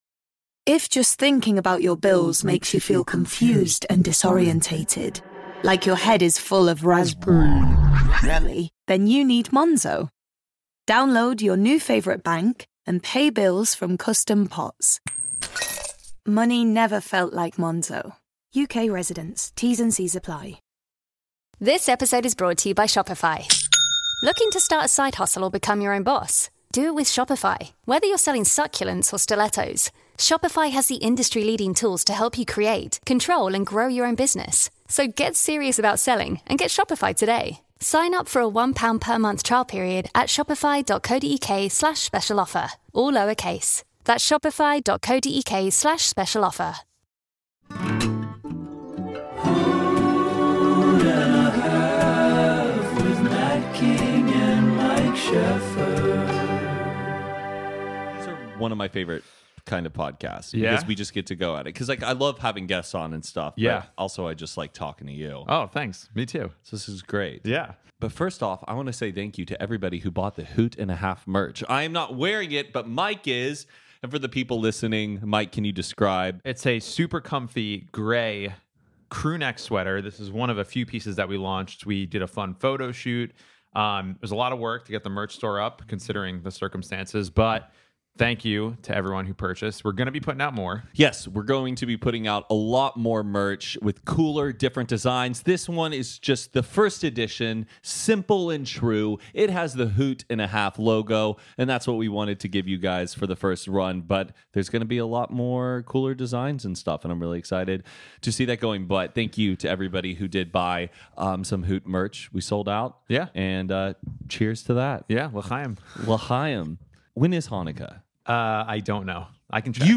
one on one conversation